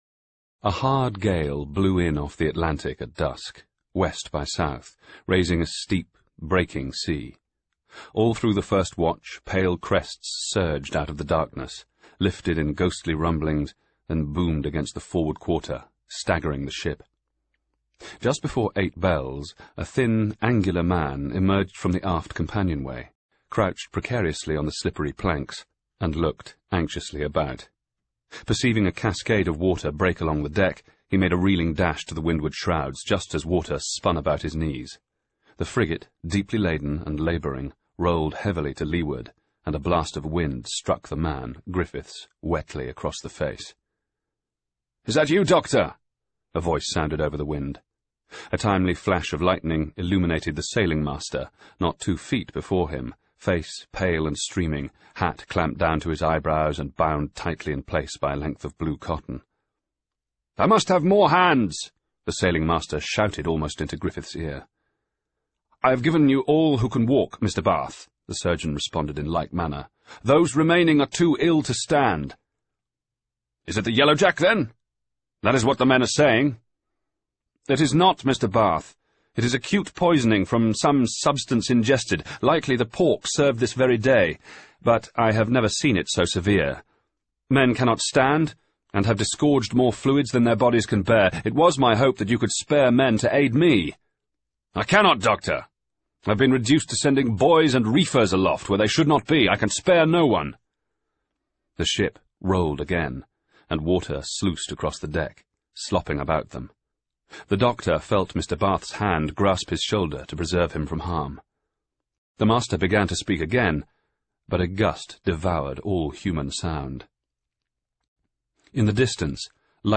Audiobook FormatCD Unabridged